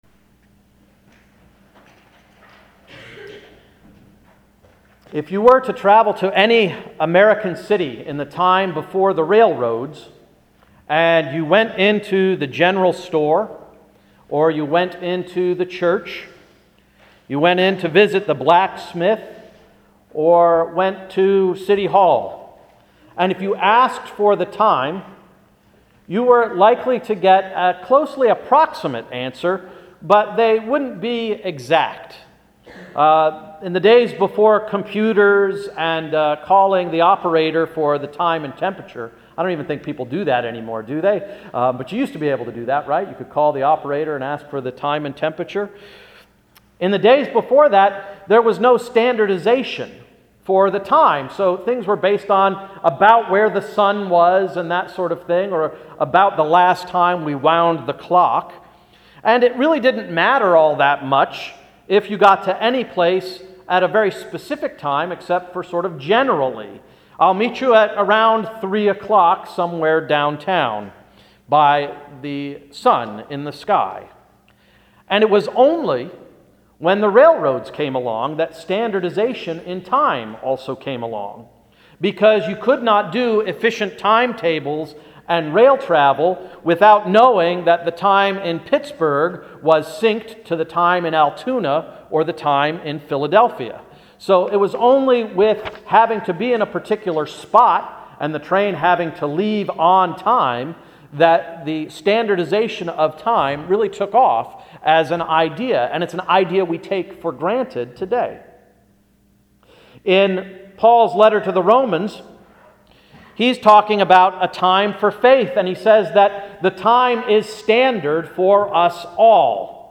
Sermon for the First Sunday of Advent–“God’s Alarm Clock” November 28, 2010